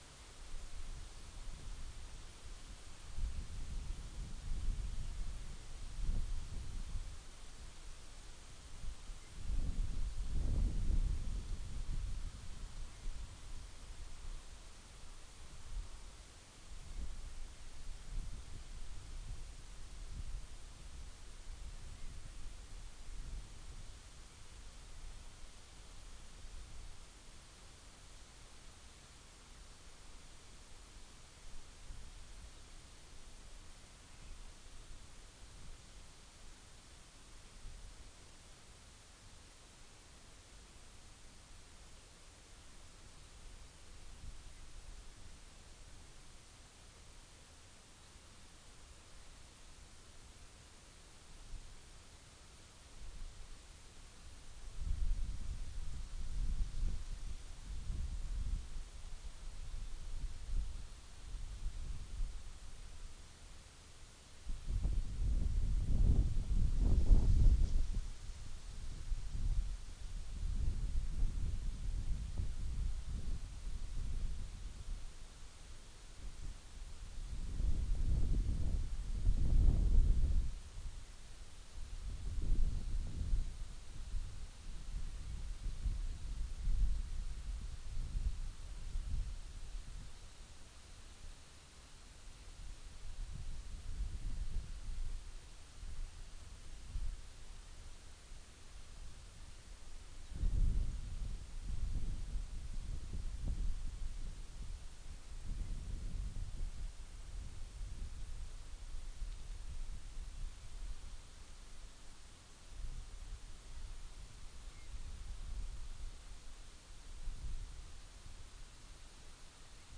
Corvus corone
Chloris chloris
Alauda arvensis
Passer domesticus